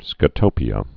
(skə-tōpē-ə)